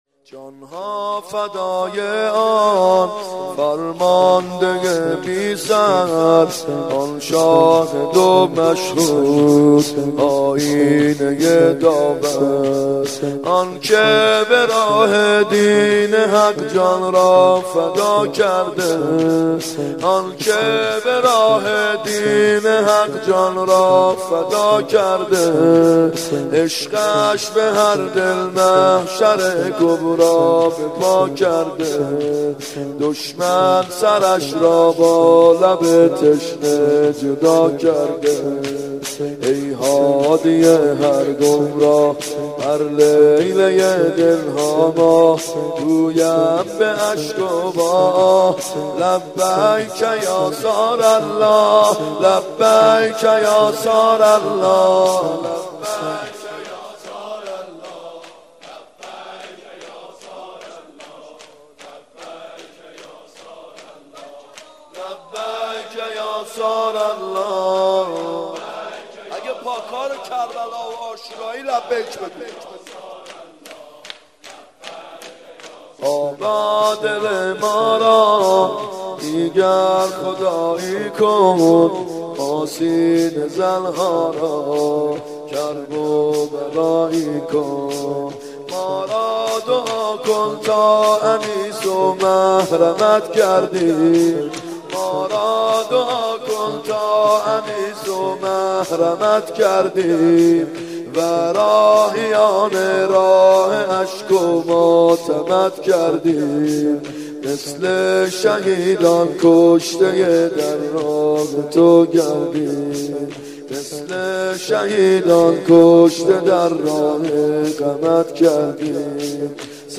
گلچین مراسمات محرم 93 شور(جان ها فدای ان فرمانده بی سر
محرم 93( هیأت یامهدی عج)